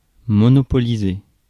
Ääntäminen
IPA: [mɔ.nɔ.pɔ.li.ze]